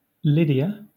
Ääntäminen
Southern England
IPA : /ˈlɪ.di.ə/